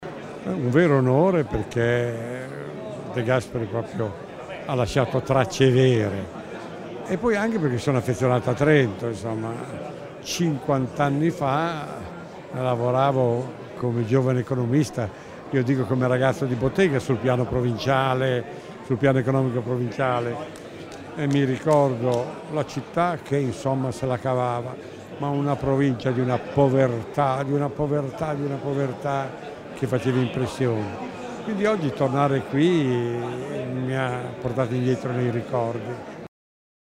Stamani la cerimonia nell'ambito della Giornata dell'Autonomia